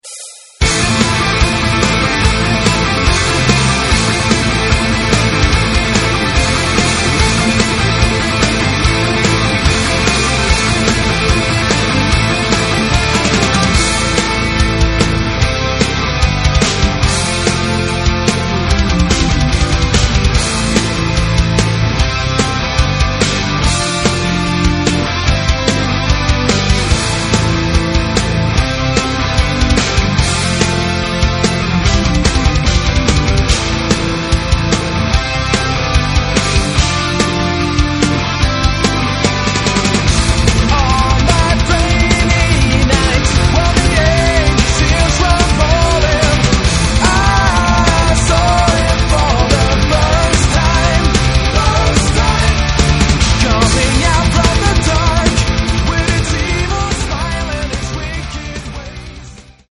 Третий альбом шведского melodic power metal коллектива.